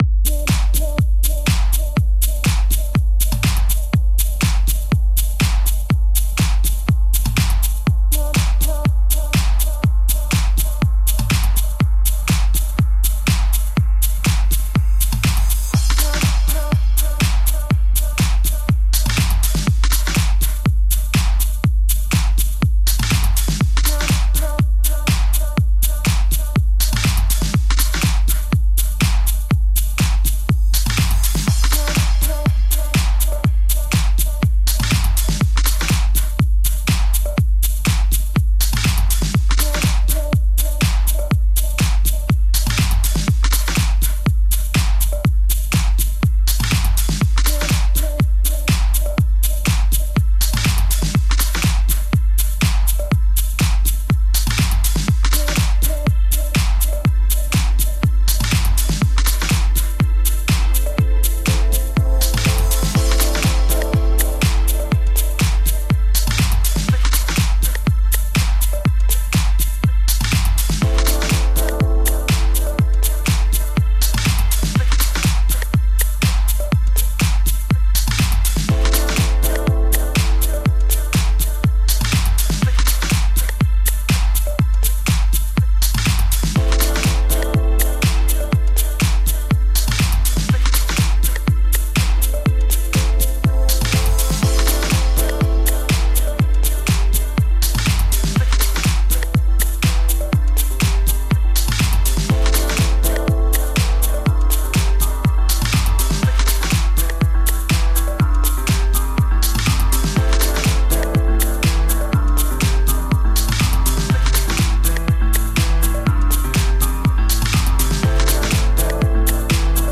House, and tech-house from the uk via japan.